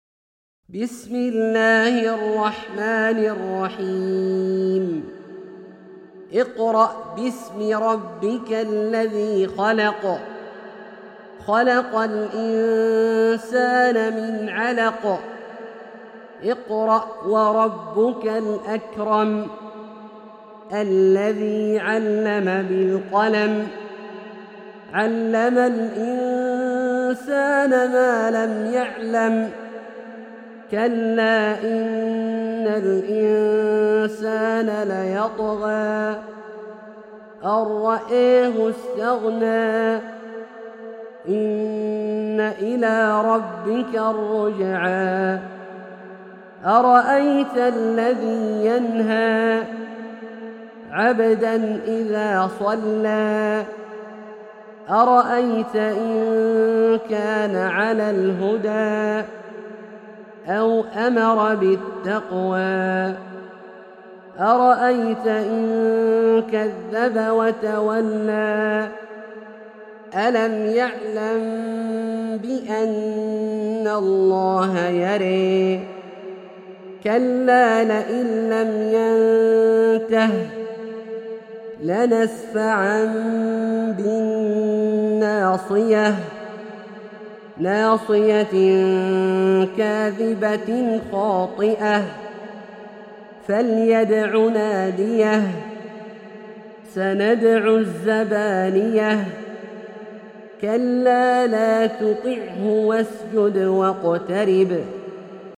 سورة العلق - برواية الدوري عن أبي عمرو البصري > مصحف برواية الدوري عن أبي عمرو البصري > المصحف - تلاوات عبدالله الجهني